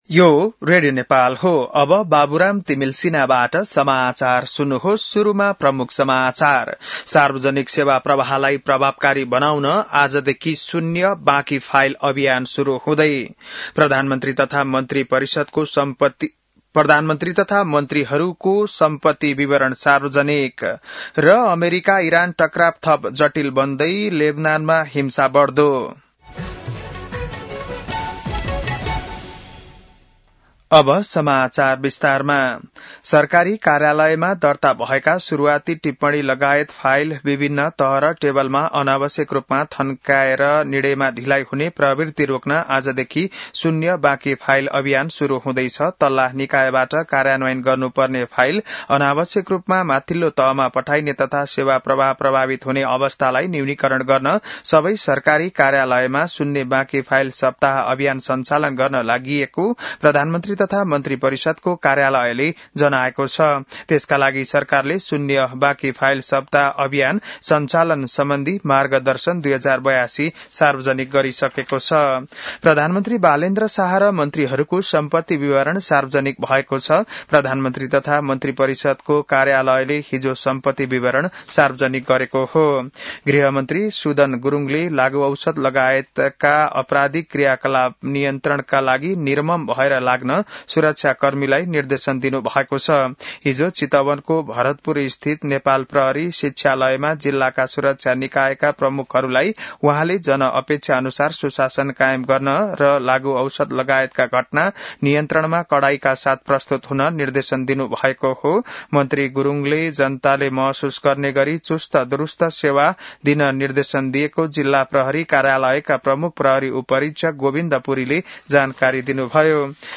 बिहान ९ बजेको नेपाली समाचार : ३० चैत , २०८२